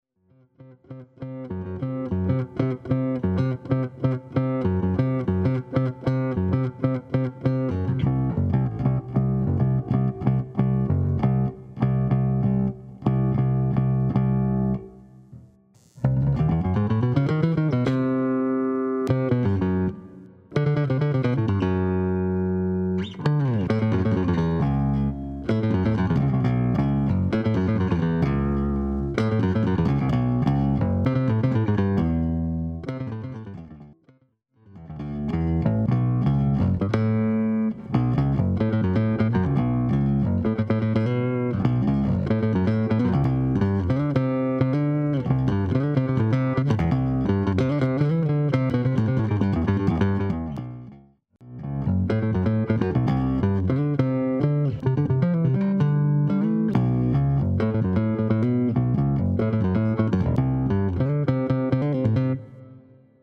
Sample  for Bass Guitar.